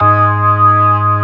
55o-org07-G#2.aif